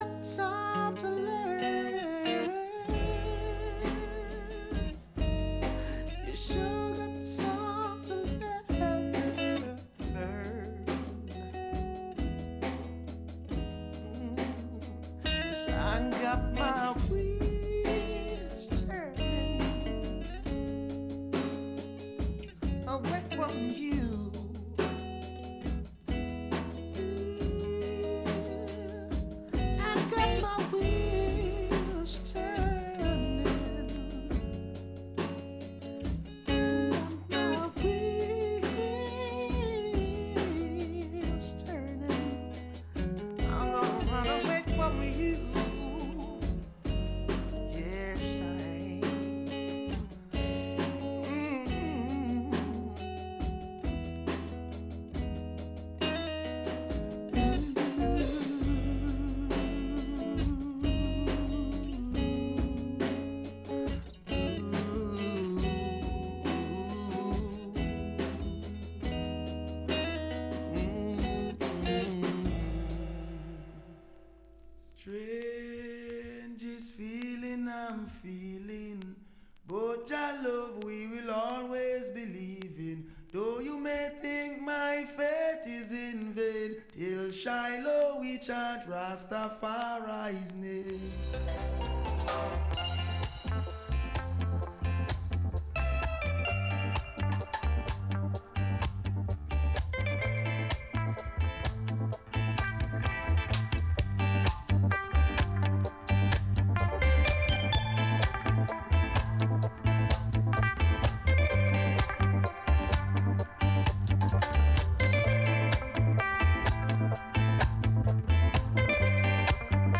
Sweet ska and rocksteady from the sixties, the deepest roots reggae from the seventies and the best dancehall from the eighties and beyond.